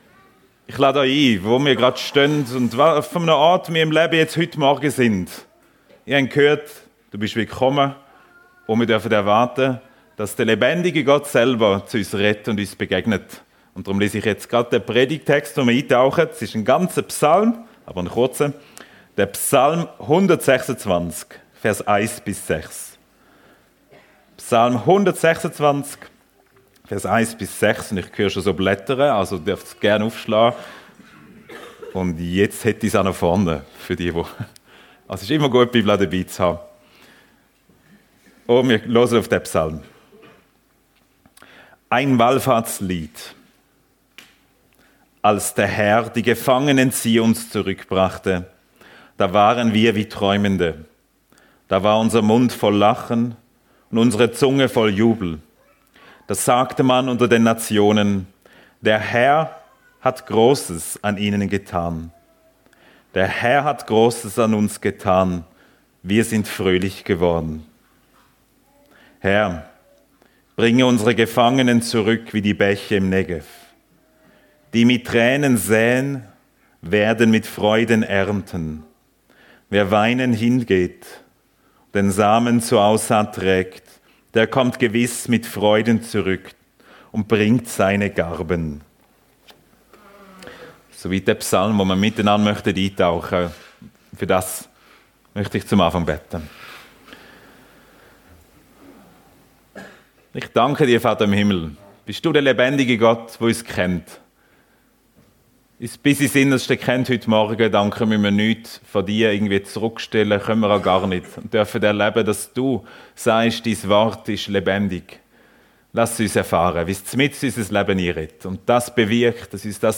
Psalm 126 - Tränen ~ FEG Sumiswald - Predigten Podcast